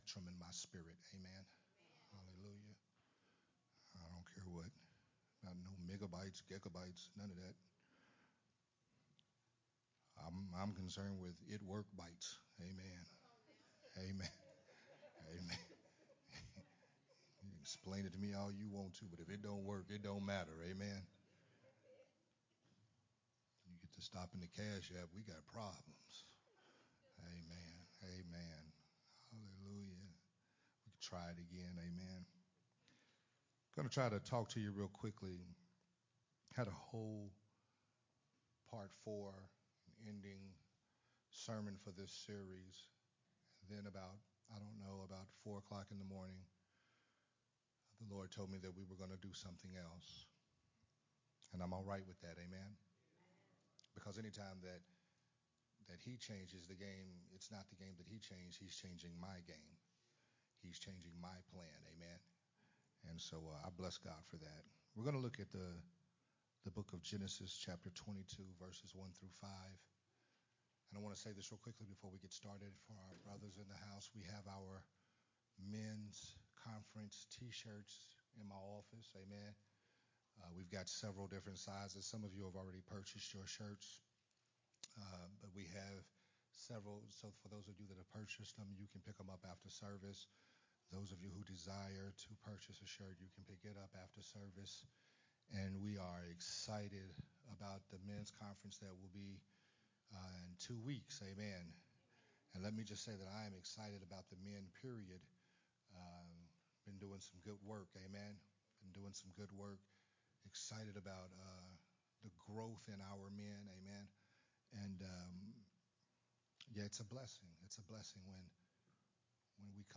Part 3 of the Consecrated sermon series
Sunday Morning Worship Service